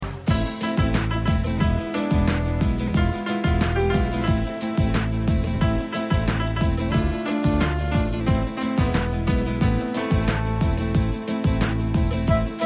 Traditional Song, Puerto Rico